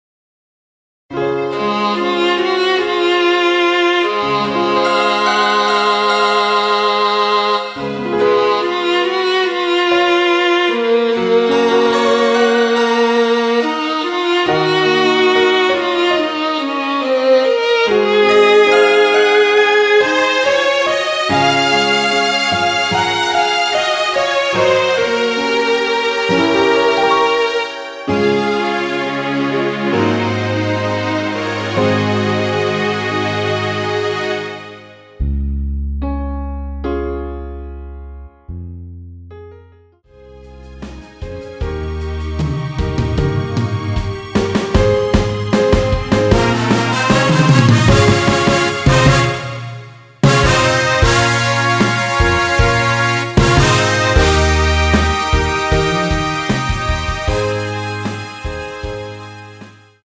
MR입니다.
앞부분30초, 뒷부분30초씩 편집해서 올려 드리고 있습니다.
중간에 음이 끈어지고 다시 나오는 이유는
위처럼 미리듣기를 만들어서 그렇습니다.